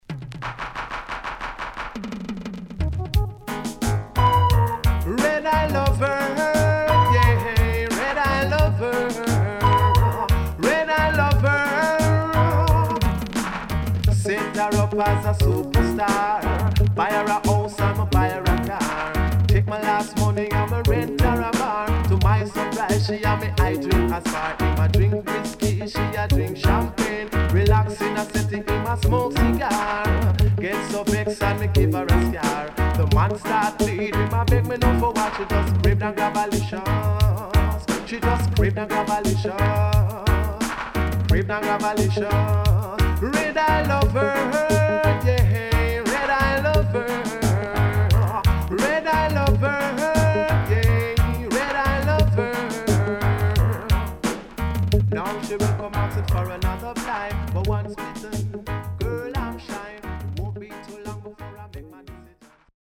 HOME > Back Order [DANCEHALL LP]
SIDE A:所々チリノイズがあり、少しプチパチノイズ入ります。